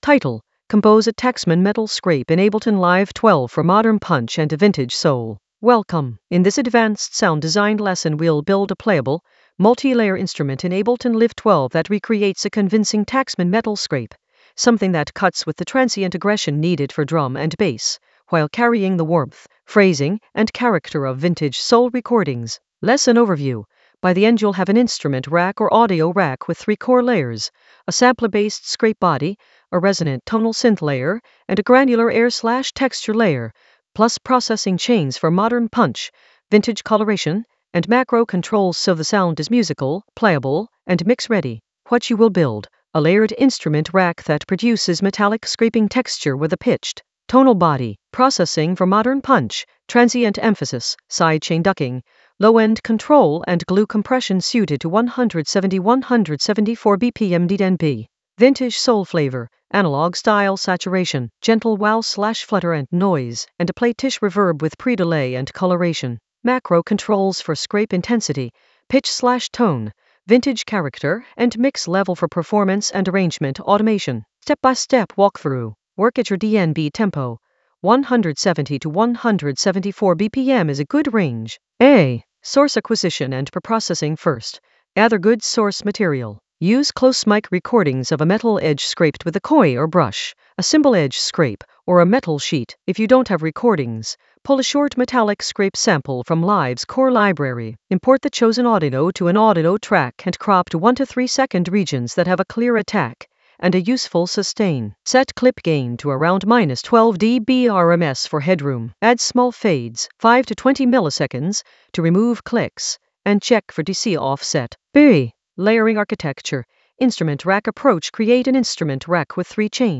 Narrated lesson audio
The voice track includes the tutorial plus extra teacher commentary.